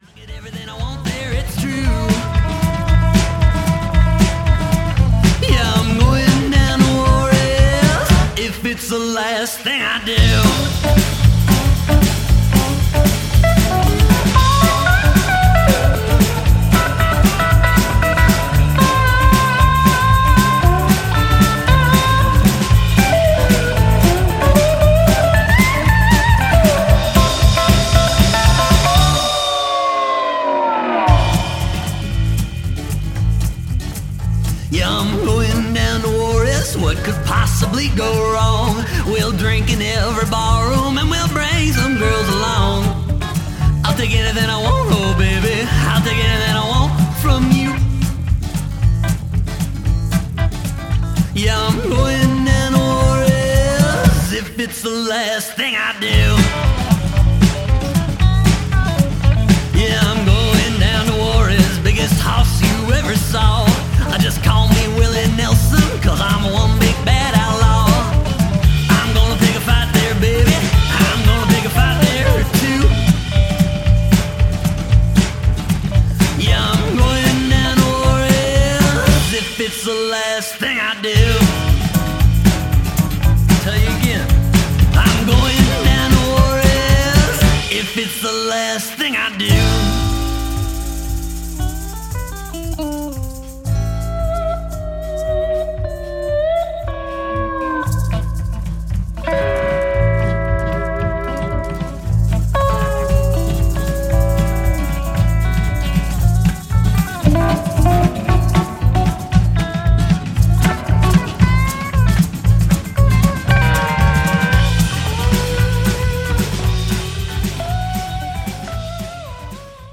Alt-Country, Folk